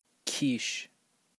Kish_pronounce.ogg.mp3